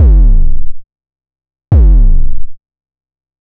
Index of /m8-backup/M8/Samples/breaks/breakcore/earthquake kicks 2
earthkik2.wav